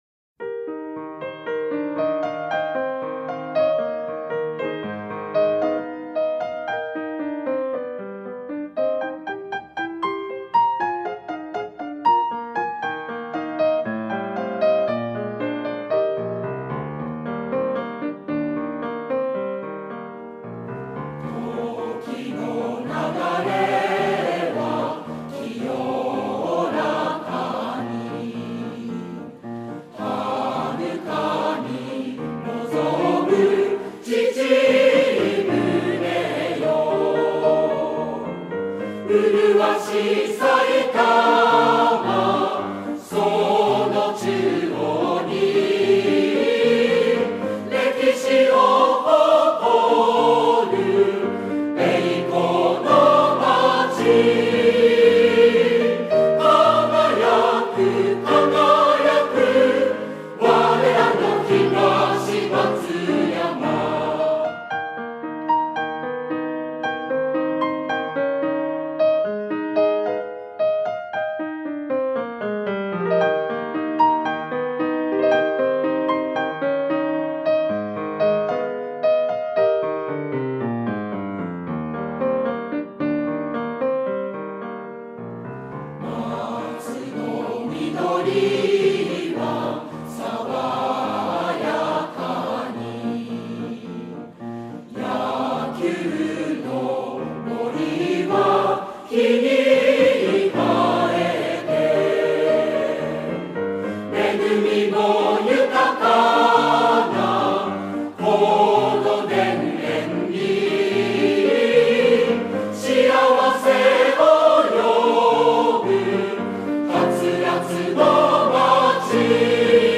2012年（平成24年）に市民の皆さんに、より市歌に親しんでいただき、市に愛着を持っていただくため、歌いやすいよう編曲を加えました。
2013年（平成25年）2月17日に市民文化センターにて、幼稚園児から80歳代の方まで幅広い年代の方に歌っていただき、録音しました。